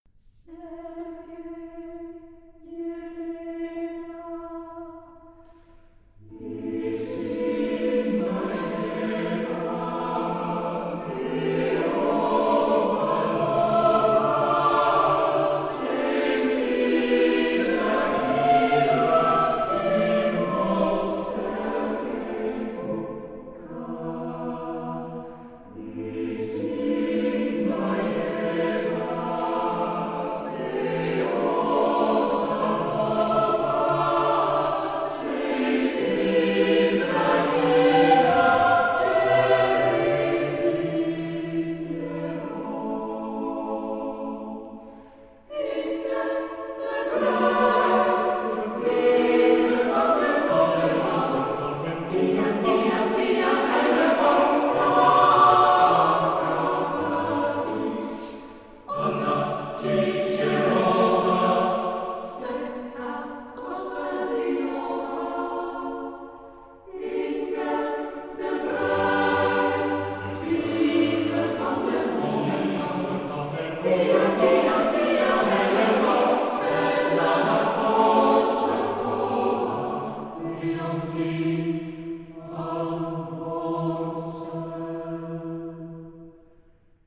Charakter des Stückes: andantino
Chorgattung: SATB  (4 gemischter Chor Stimmen )
Tonart(en): a-moll